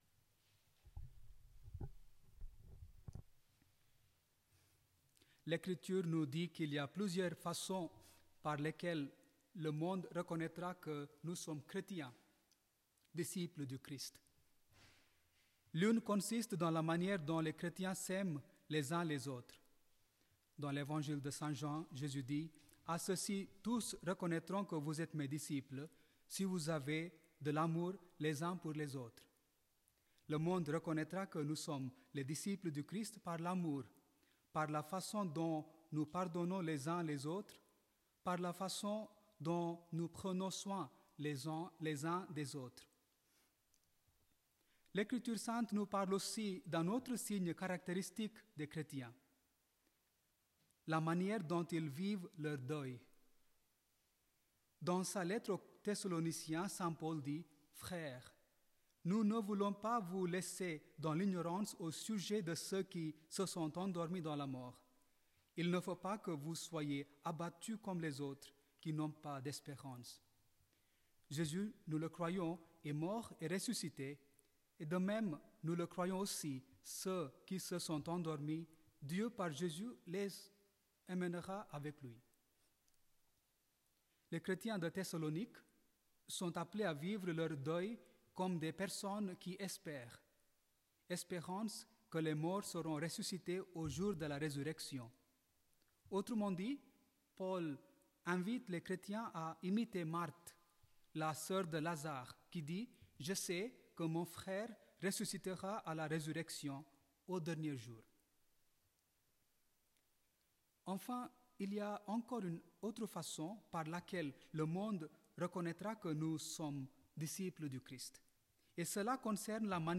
Il partage avec nous son homélie sur l'Évangile selon saint Jean 7, 40-53 pour la messe d'aujourd'hui dans la chapelle du couvent St-Hyacinthe.